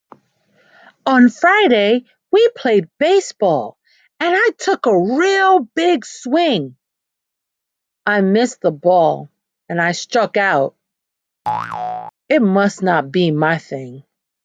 Story telling from enthusiastic teachers.